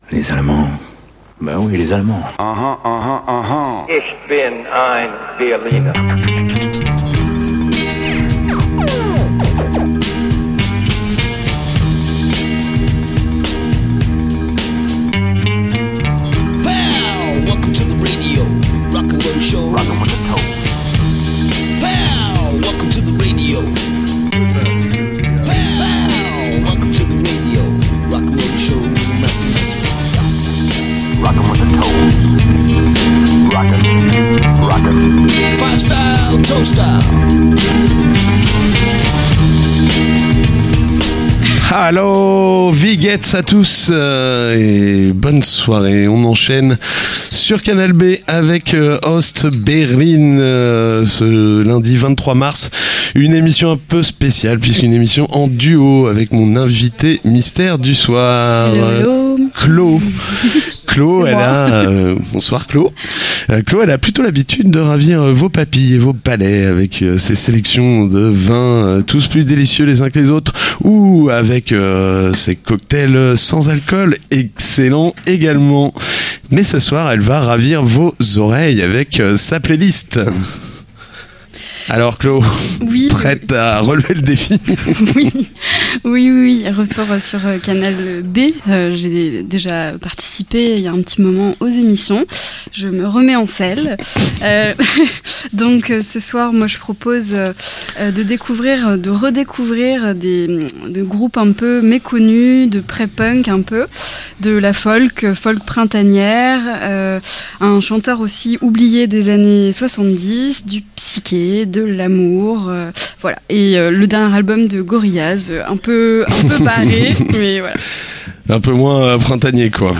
Toujours là pour vous faire découvrir en exclusivité mondiale les meilleurs morceaux de demain et vous faire redécouvrir les meilleurs morceaux d'hier. Entre deux morceaux les deux animateurs déblatèrent quelques inepties et étalent le plus largement possible le peu de culture qu'ils ont.